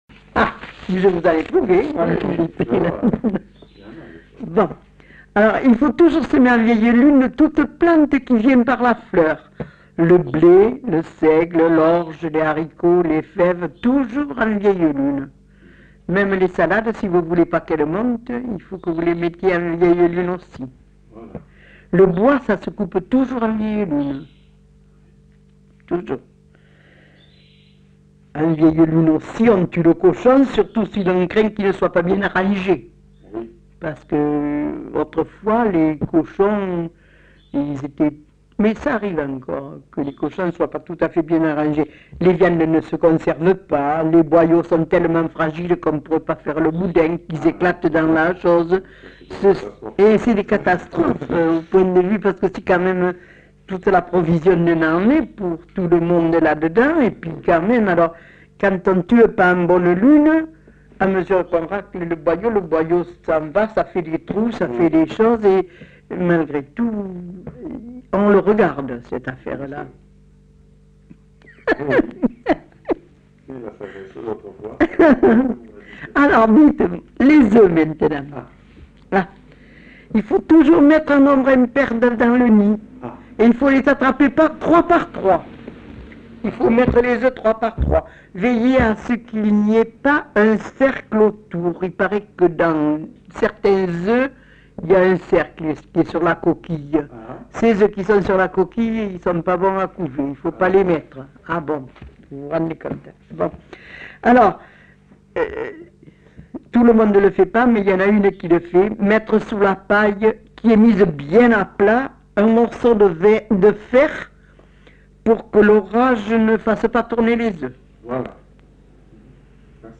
Lieu : Grignols
Genre : témoignage thématique